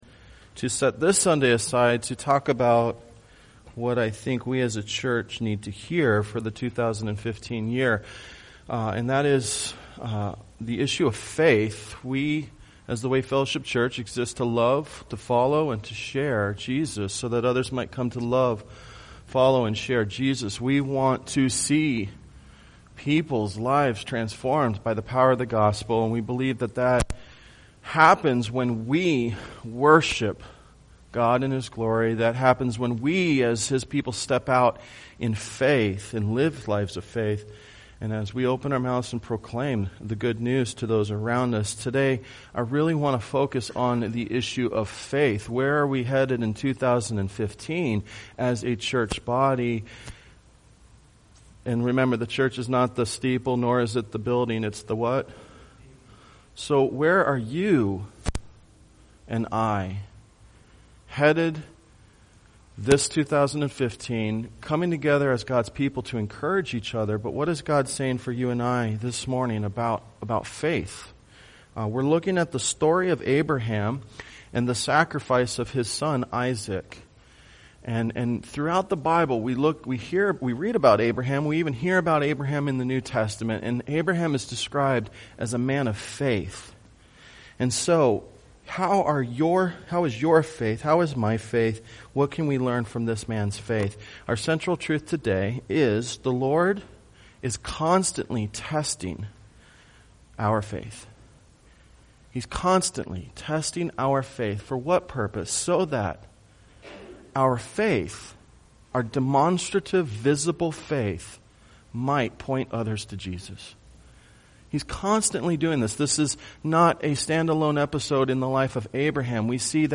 Sunday Worship
Tagged with Sunday Sermons